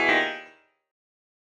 システム系の効果音です！ちょっと微妙…な展開やセリフなどのときにぴったり！
iffy.mp3